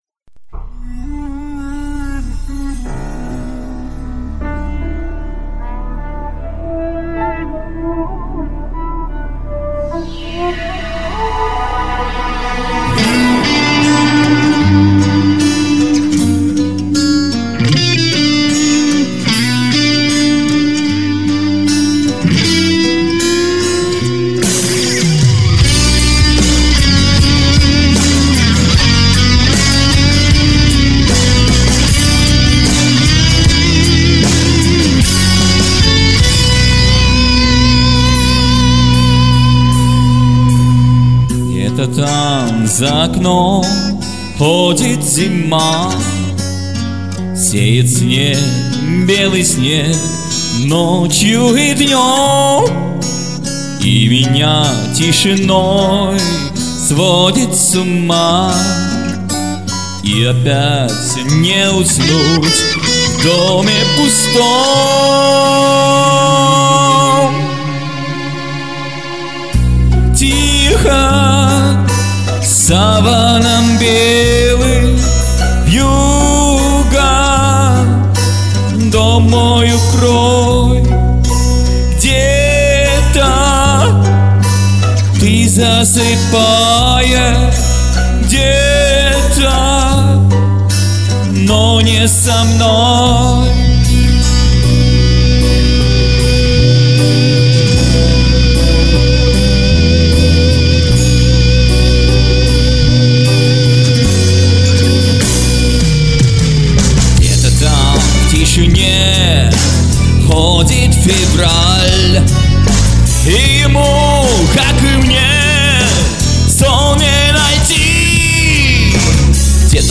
Îé, ìàìà, êàêèå ïèêàíòíûå èíòîíàöèè... :)
Äà è ãîðëî çðÿ äåð¸øü, ïûòàÿñü äðàéâèòü.